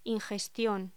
Locución: Ingestión